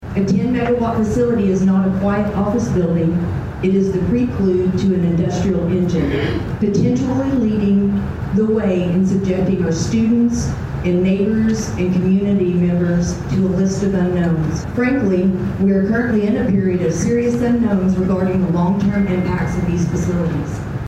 In a standing room only crowd that reached full capacity at the Dave Landrum Community Center, 14 Pawhuska residents spoke out against the possibility of a small scale data center coming to Pawhuska.